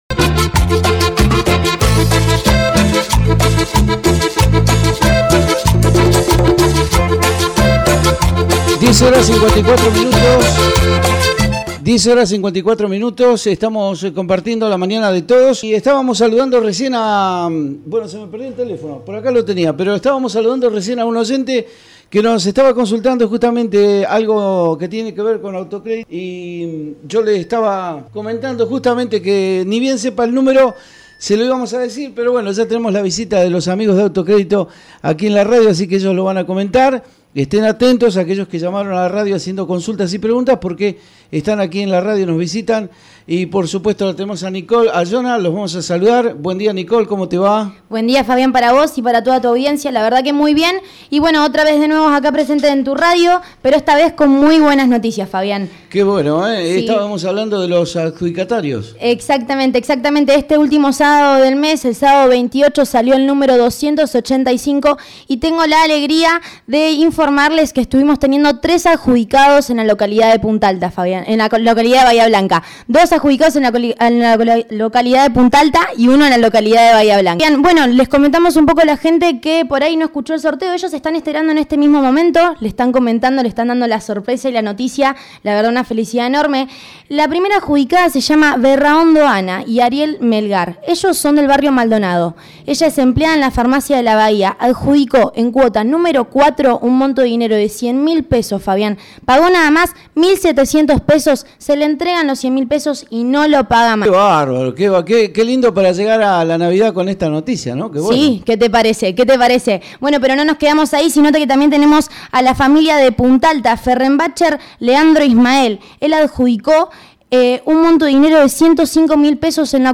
FacebookXWhatsApp AUTOCREDITO DA A CONOCER LOS NOMBRES DE LOS ADJUDICATARIOS DEL ULTIMO SORTEO . AUDIO AUTOCREDITO EN LA RADIO